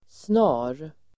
Uttal: [sna:r]